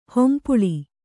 ♪ hompuḷi